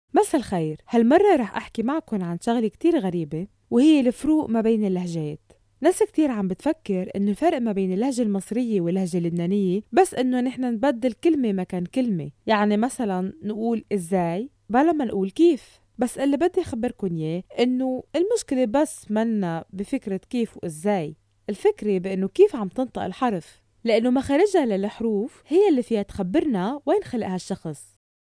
Lübnan Arapçası Seslendirme
Kadın Ses